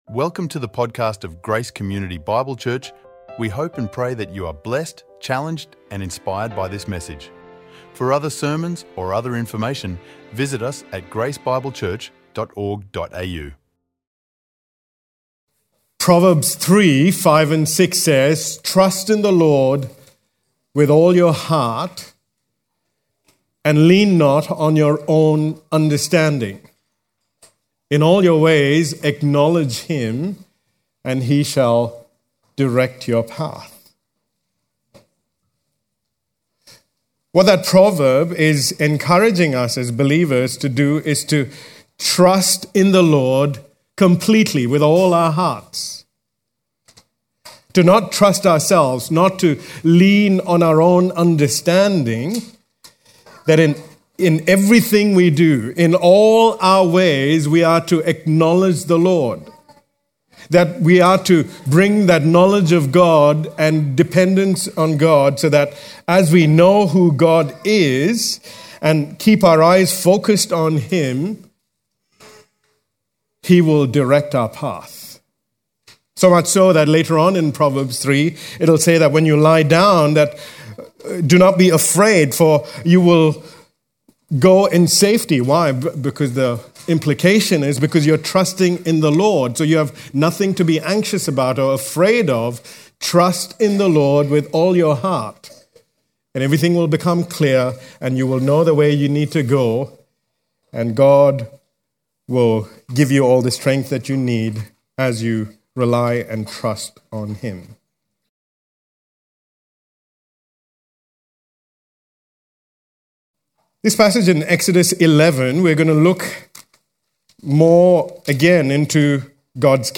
recorded live at Grace Community Bible Church, on the topic “The Coming Final Plague” – from Exodus 11 as a part of the “Book of Exodus” series.